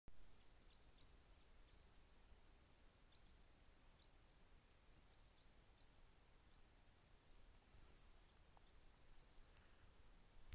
Common Crossbill, Loxia curvirostra
Count4 - 5